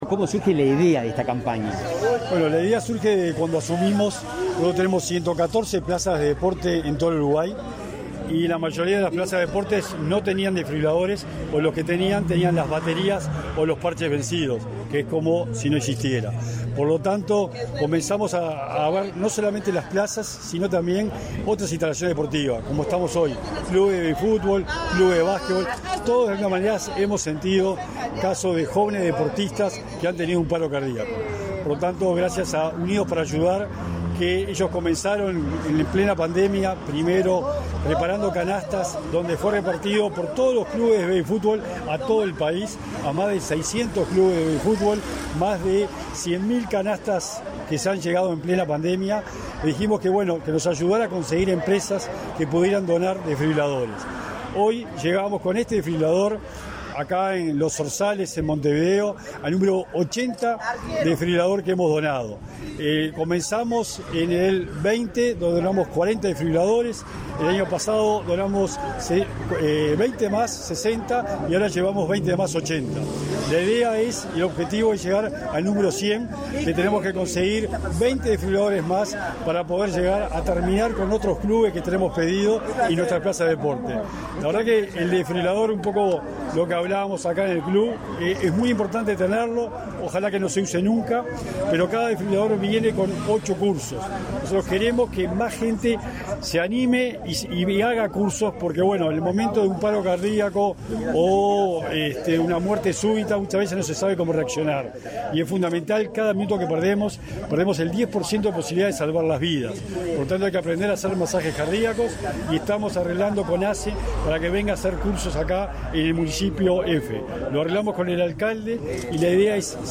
Entrevista al secretario nacional del Deporte, Sebastián Bauzá